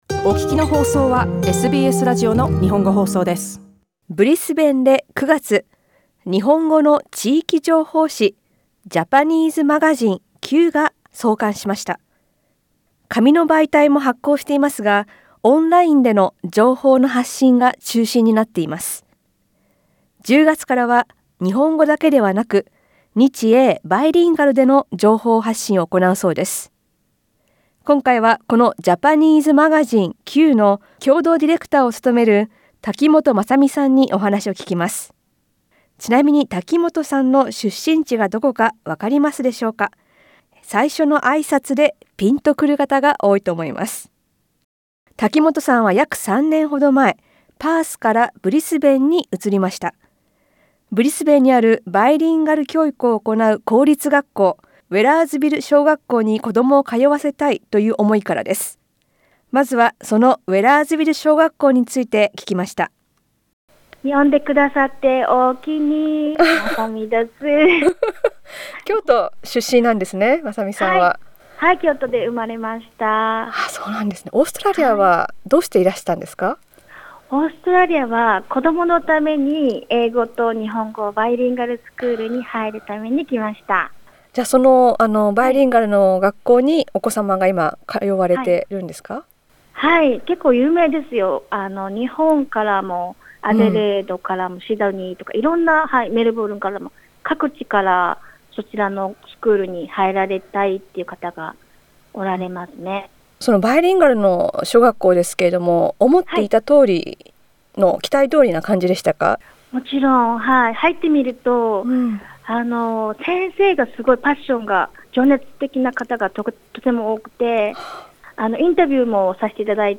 インタビューでは、ブリスベンにあるバイリンガル小学校の話から、情報誌創刊の経緯、今後目指すことなどを聞きました。